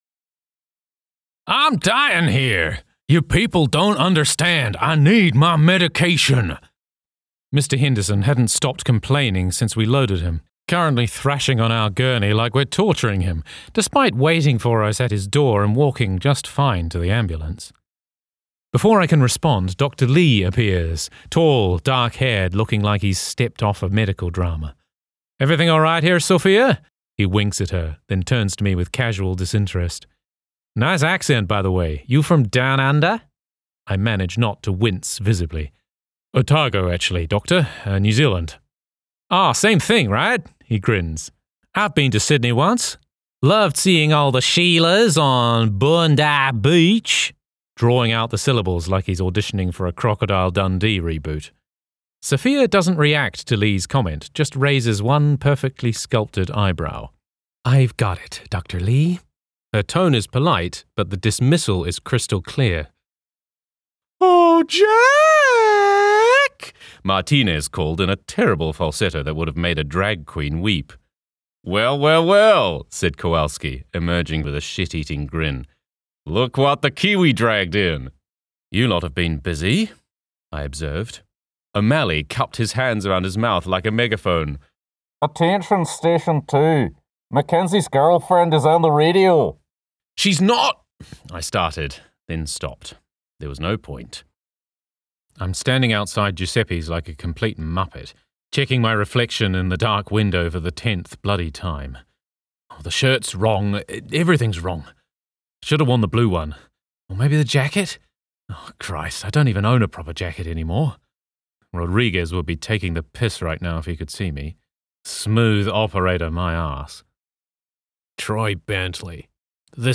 Authentic  |  Warm  |  Versatile
Audiobook Narration